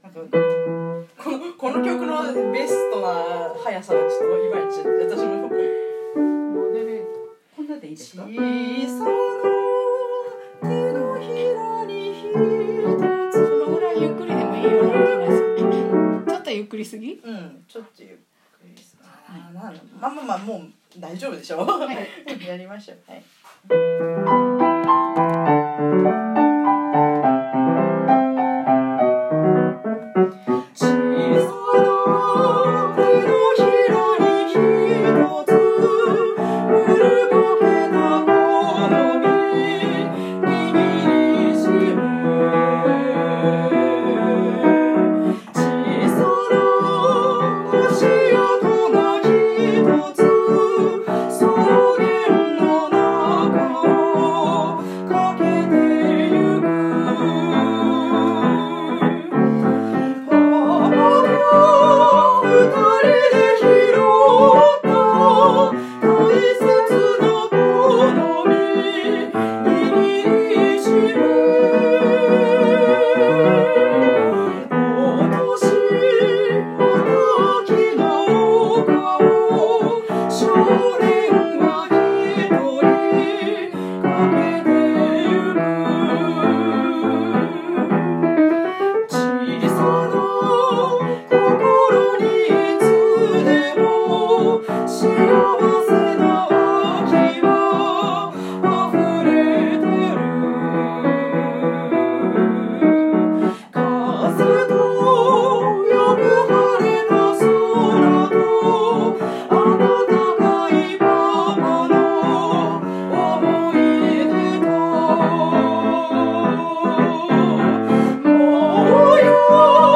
今日はパート練習と音源作成！